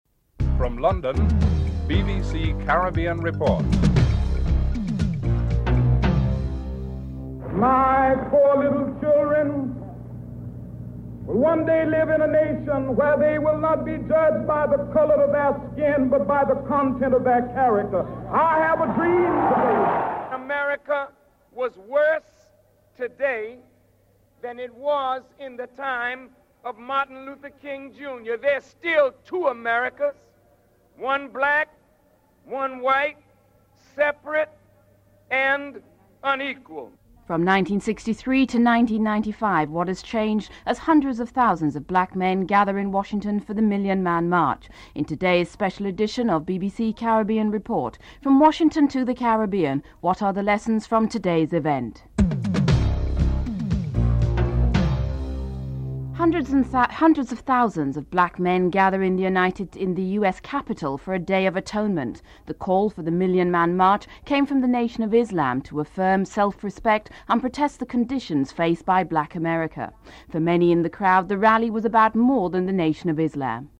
3. Report on what impact the march is having outside of Washington (04:36-07:45)
7. Louis Farrakhan comments on where he sees this event leading (14:45-15:10)